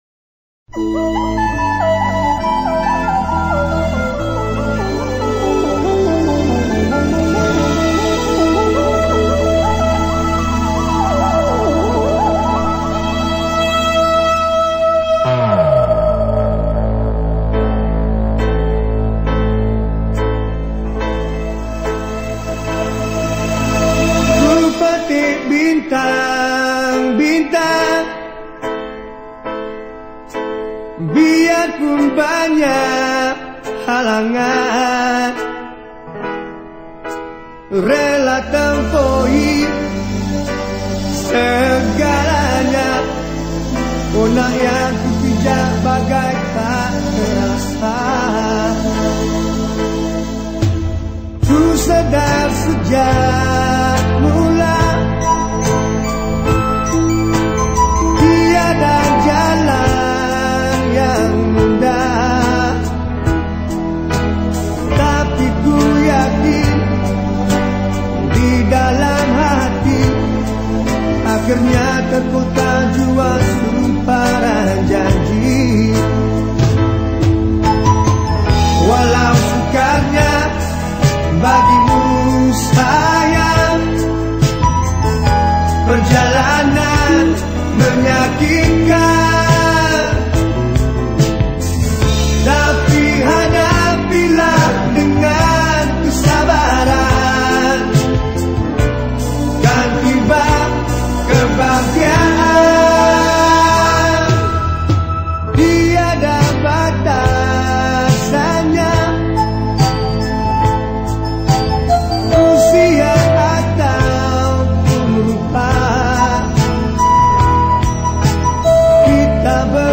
Lagu Patriotik Malaysia
Skor Angklung